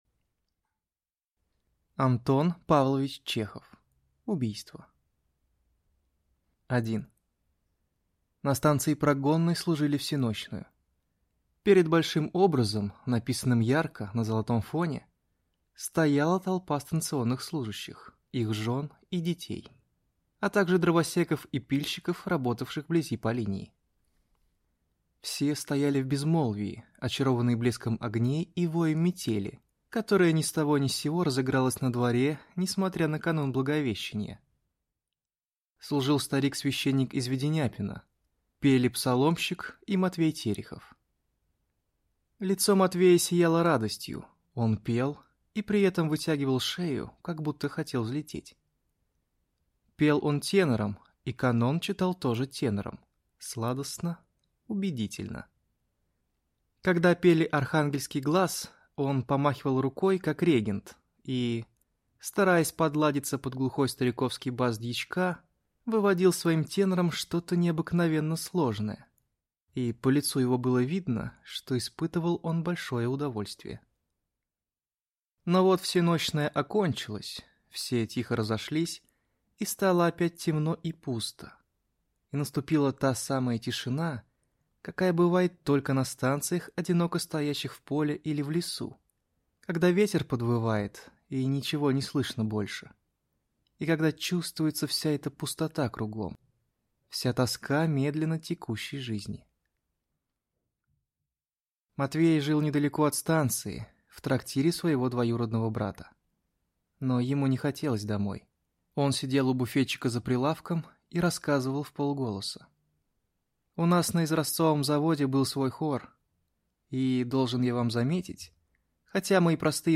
Аудиокнига Убийство | Библиотека аудиокниг